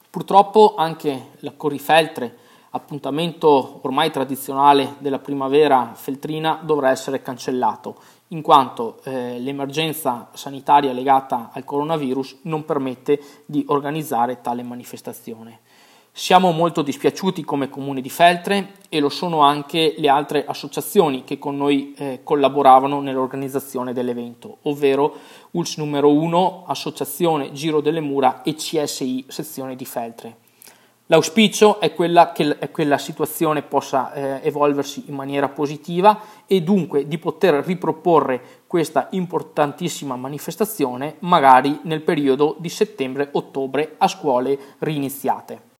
IL PRESIDENTE DEL CONSIGLIO COMUNALE DI FELTRE MANUEL SACCHET